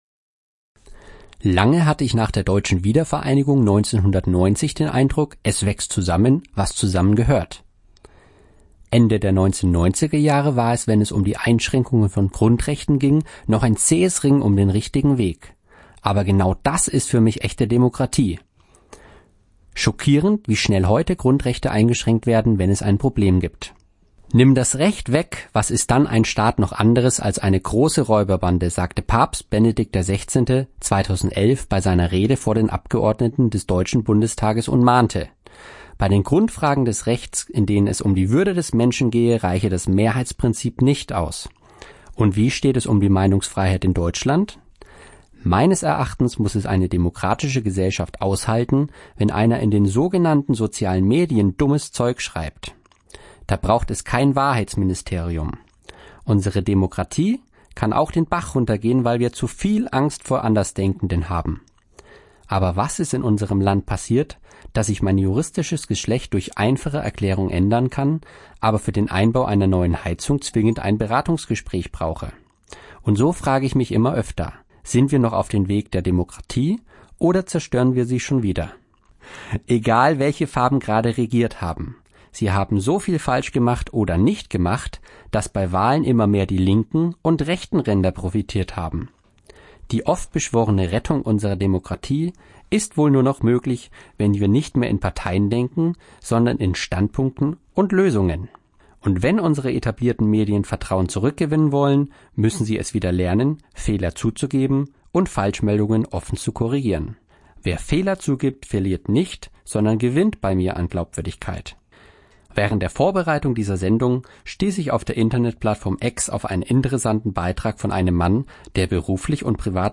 Kommentar zur Deutschen Einheit